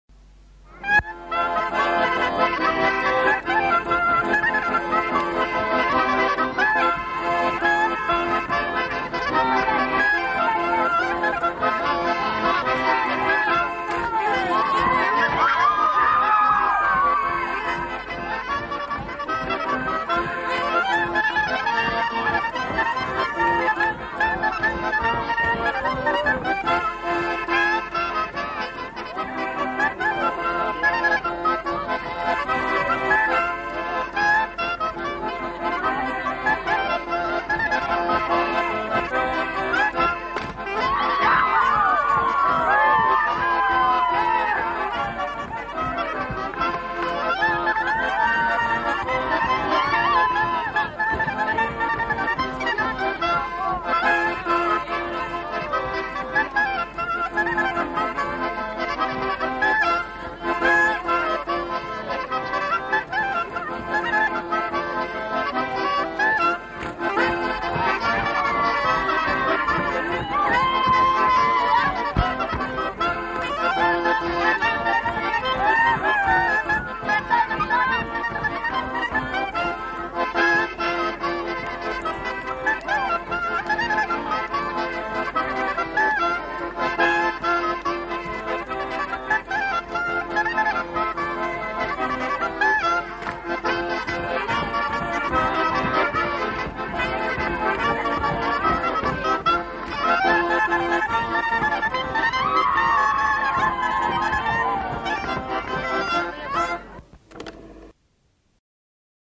Instrumental. Accordéon chromatique, clarinette.
Aire culturelle : Val Vermenagna
Lieu : Limone
Genre : morceau instrumental
Instrument de musique : clarinette ; accordéon chromatique
Notes consultables : Les deux musiciens ne sont pas identifiés.